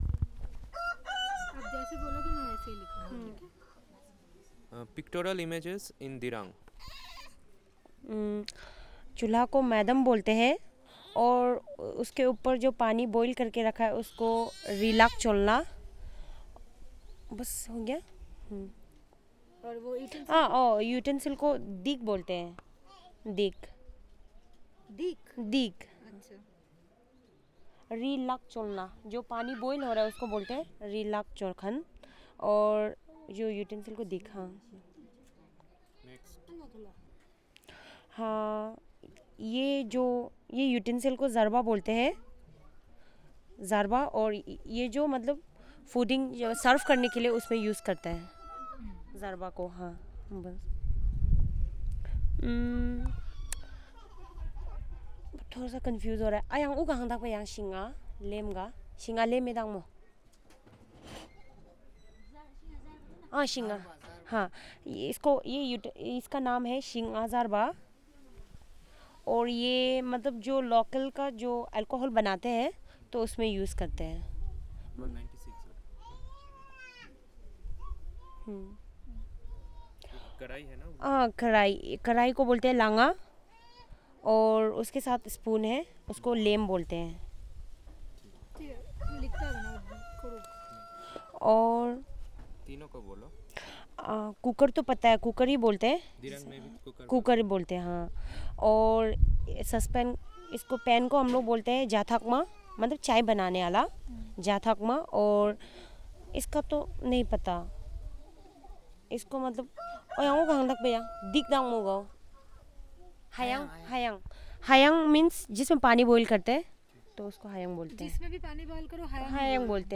Elicitation of words about household items